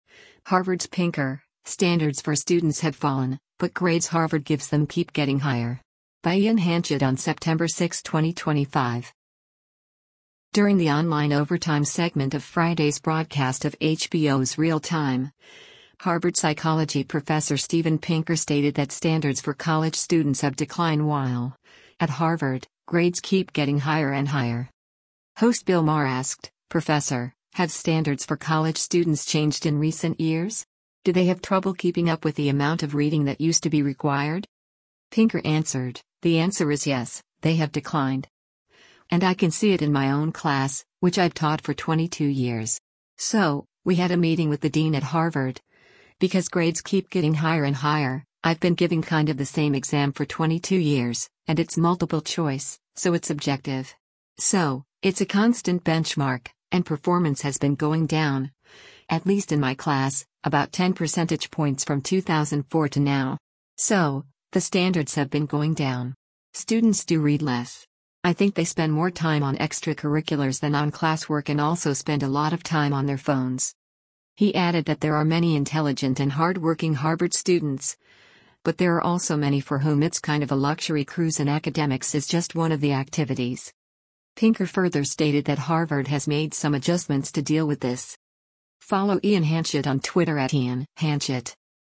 During the online “Overtime” segment of Friday’s broadcast of HBO’s “Real Time,” Harvard Psychology Professor Steven Pinker stated that standards for college students have declined while, at Harvard, “grades keep getting higher and higher.”
Host Bill Maher asked, “Professor, have standards for college students changed in recent years? Do they have trouble keeping up with the amount of reading that used to be required?”